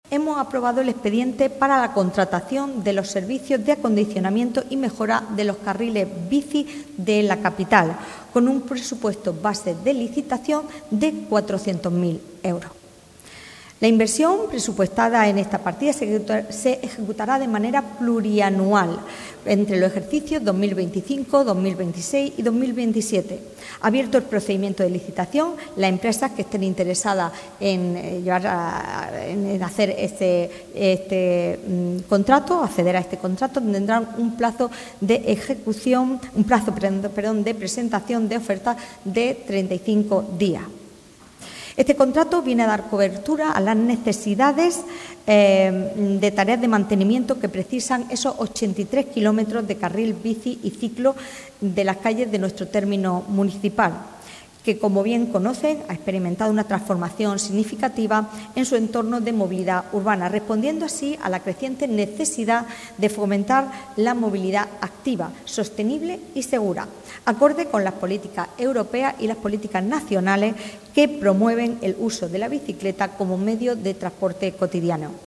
Del contenido de este acuerdo ha informado hoy la portavoz del Equipo de Gobierno, Sacramento Sánchez, recordando que “con más de 83 kilómetros de carriles bici y ciclo calles, Almería ha experimentado una transformación significativa en su modelo de movilidad urbana, fomentando el uso de la bicicleta como medio de transporte que puede ser empleado en los recorridos por la ciudad”.
CORTE-SACRAMENTO-SANCHEZ-CARRILES-BICI.mp3